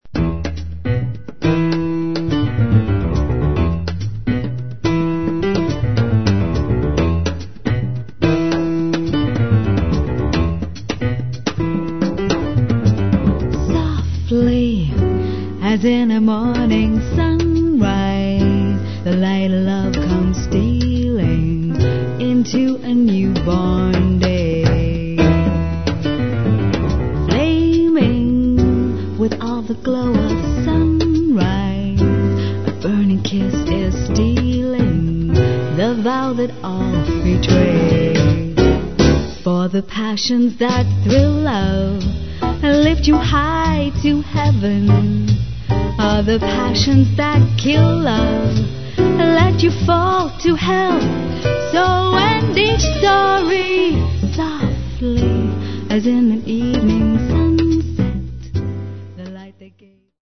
vocals
piano
double bass
drums
saxophone